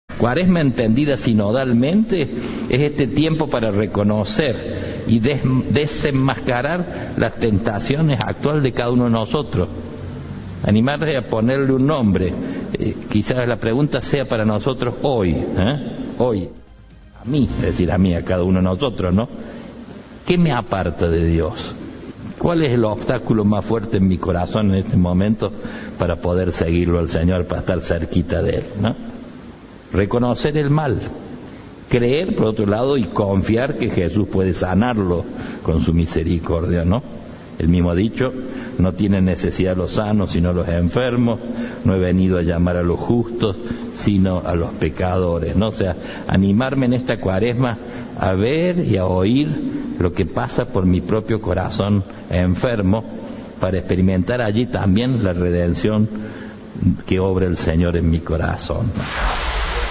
Santa Misa